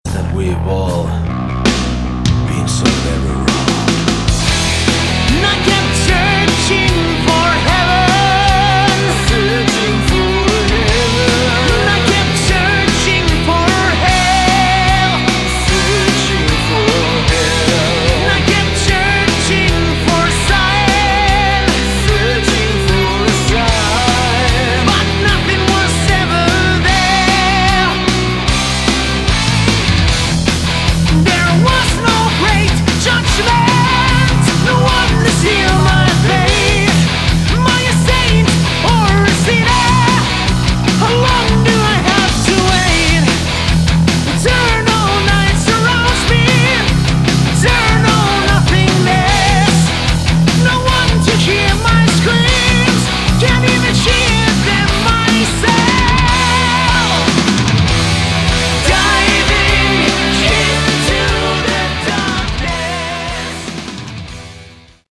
Category: Melodic Metal
lead vocals
guitars, backing vocals
bass, backing vocals
drums